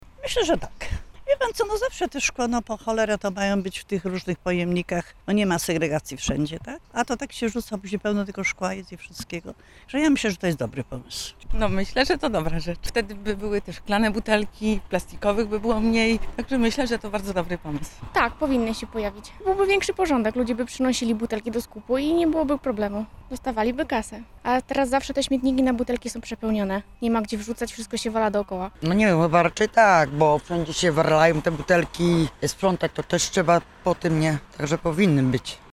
Spytaliśmy zielonogórzan, czy uruchomienie takiego punktu byłoby zasadne. Okazuje się, że pomysł spotkał się z ogromnym entuzjazmem: